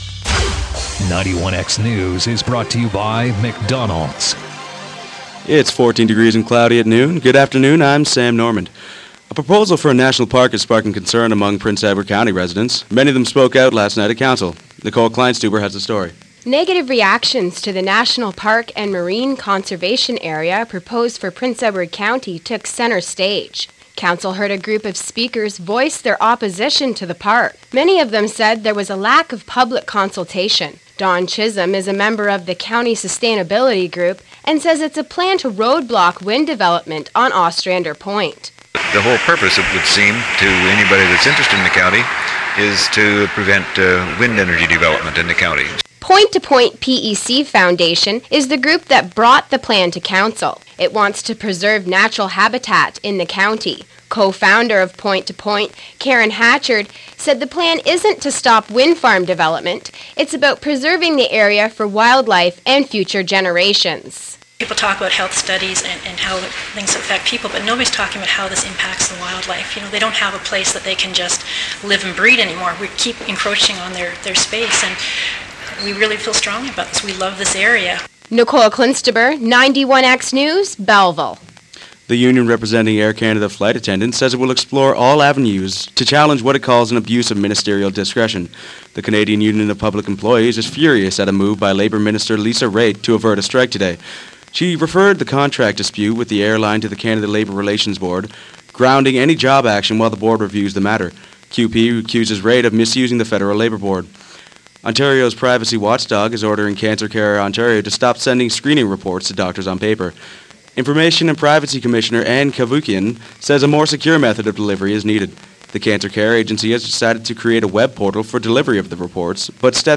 91x News